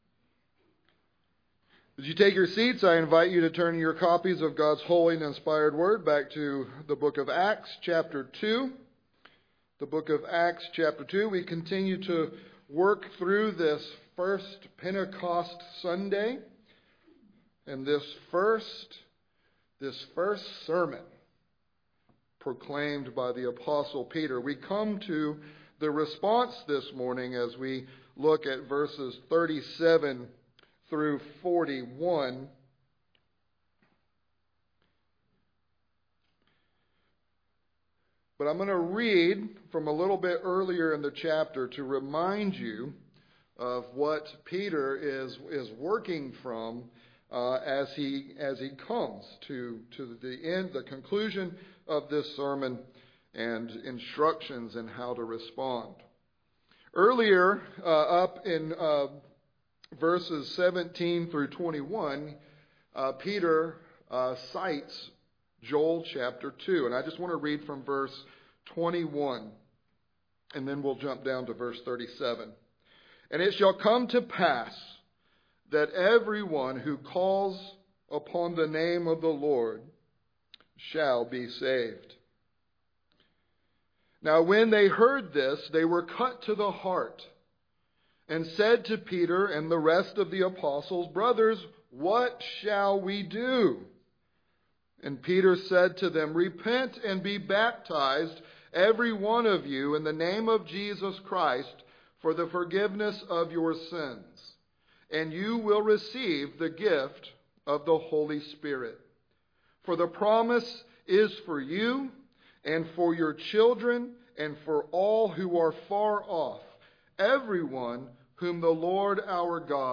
Repent and Be Baptized: You, Your Children, and the Foreigner ~ Sermons Podcast